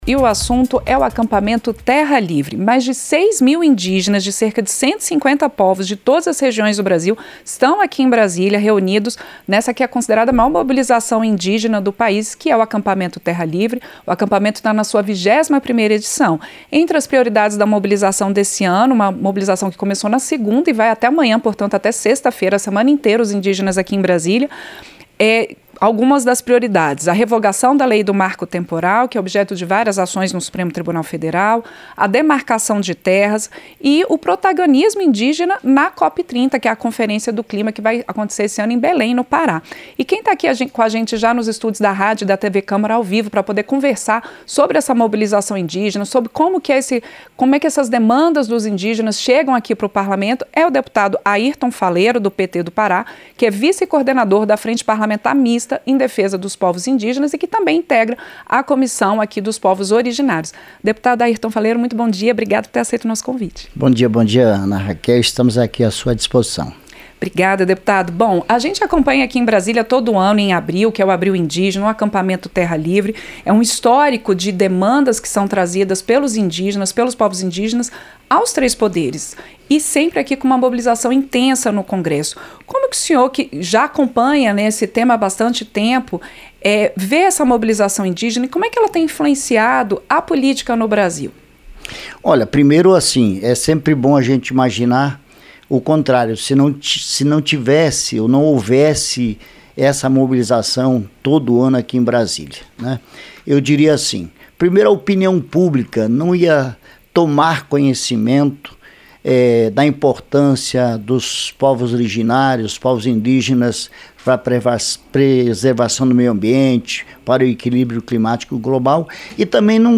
Entrevista - Dep. Airton Faleiro (PT-PA)